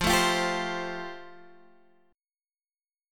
Fadd9 Chord (page 2)
Listen to Fadd9 strummed